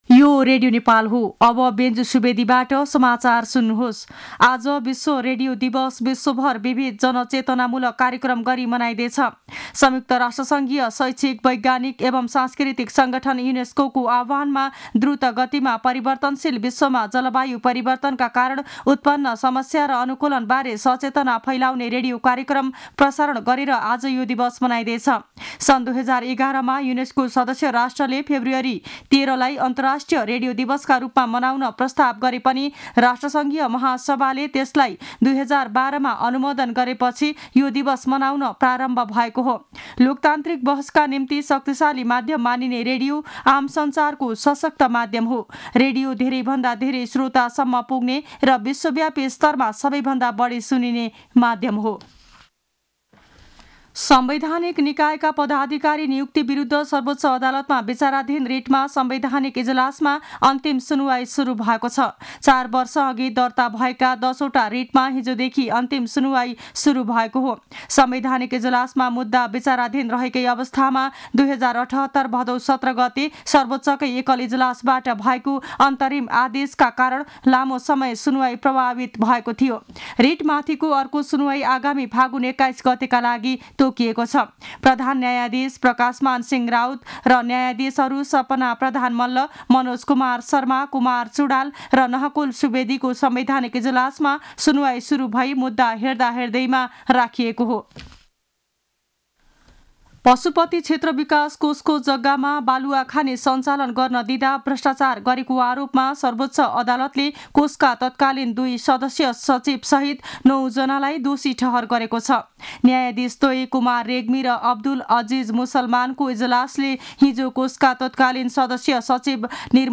मध्यान्ह १२ बजेको नेपाली समाचार : २ फागुन , २०८१